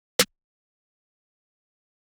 snare 8.wav